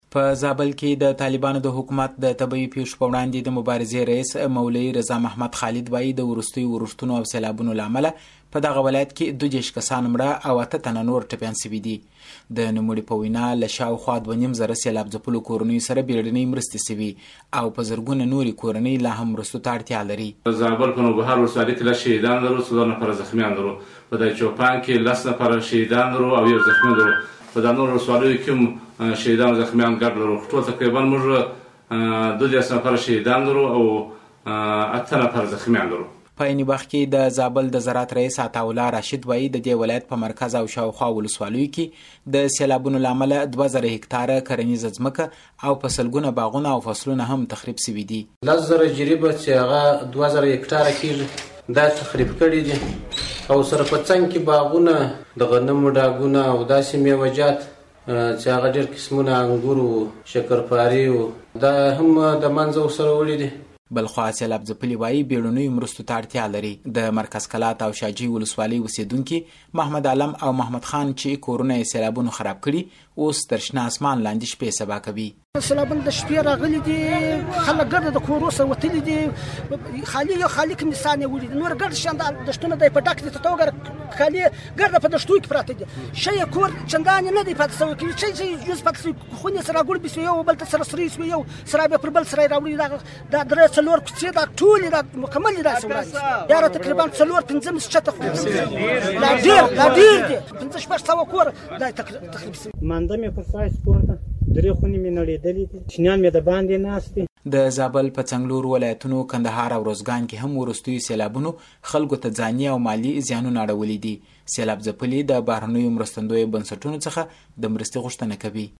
د زابل راپور